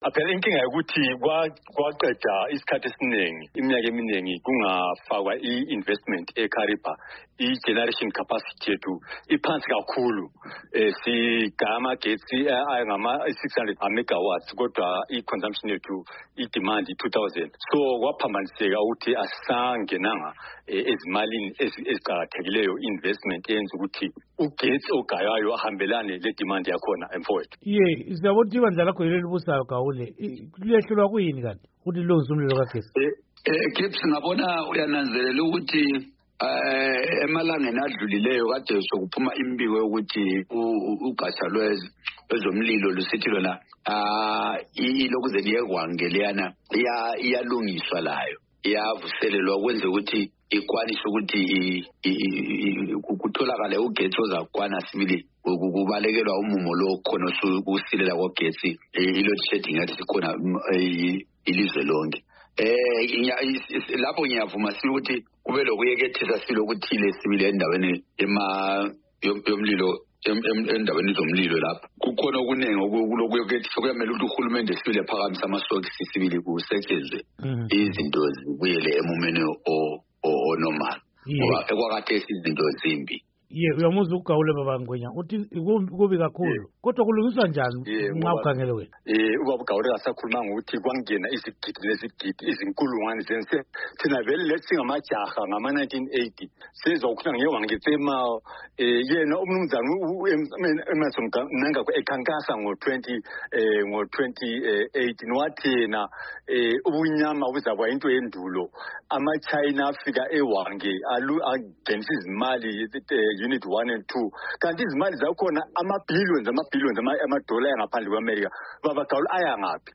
Ingxoxo Esiyenze LoMnui.